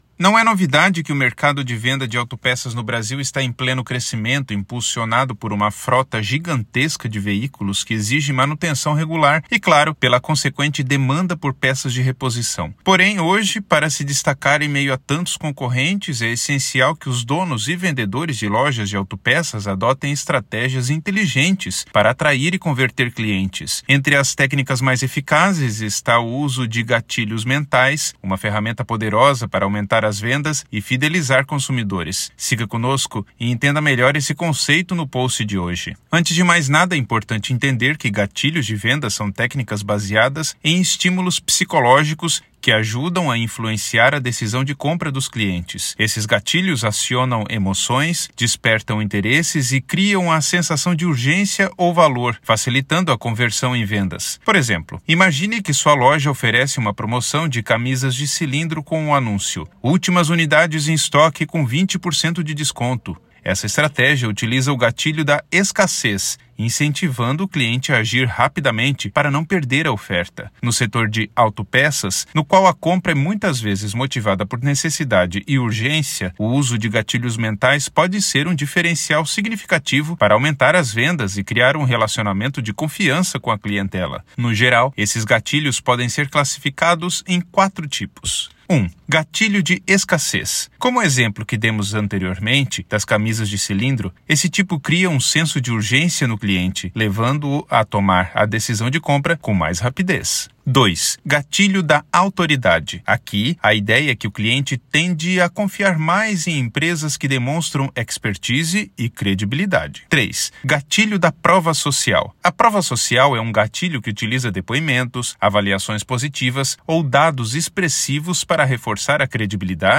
Narracao-04-gatilhos-de-vendas.mp3